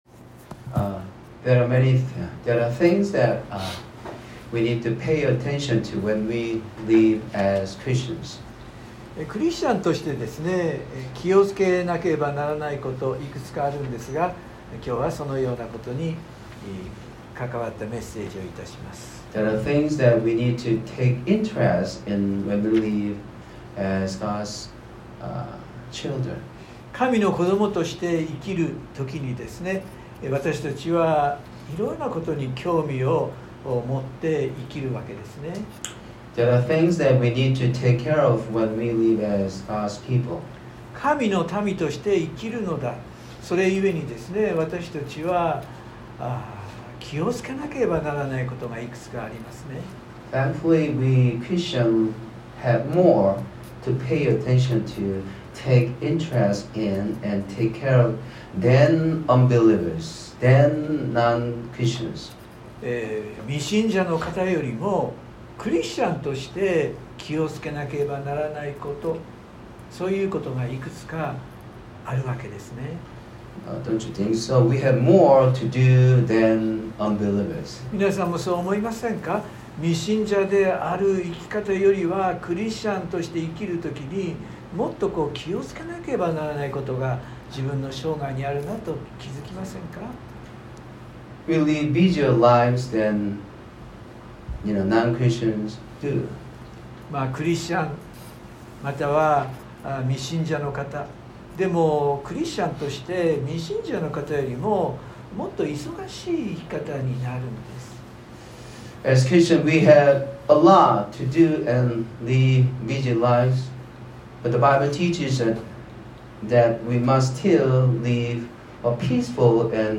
（日曜礼拝録音）【iPhoneで聞けない方はiOSのアップデートをして下さい】原稿は英語のみになります。
（上のバーから聞けない方は青いボタンから） iPhone 宣教師メッセージ 礼拝メッセージ シェアする X Facebook はてブ LINE コピー インターナショナル・バイブル・フェローシップ